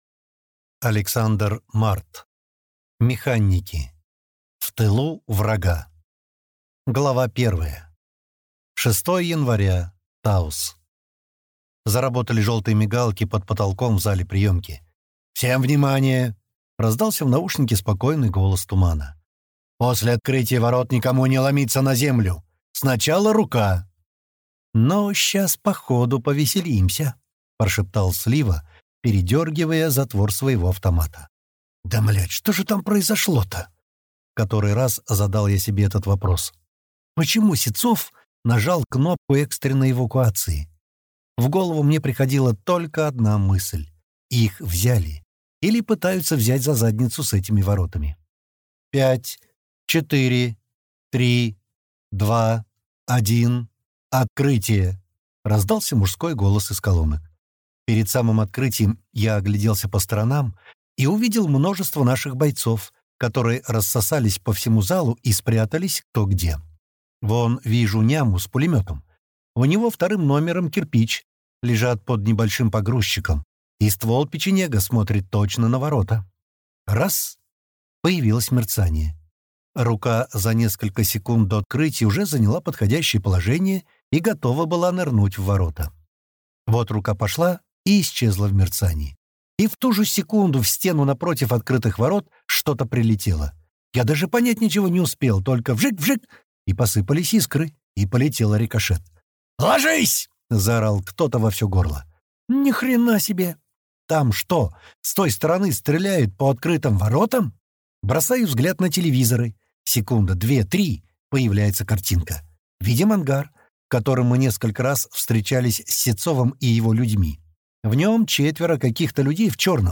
Аудиокнига Механики. В тылу врага | Библиотека аудиокниг